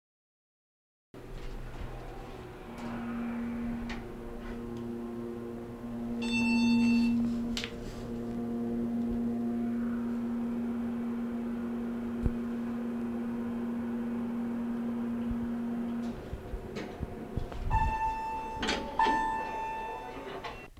elevatordingsnd
ambient ding elevator moving sound effect free sound royalty free Nature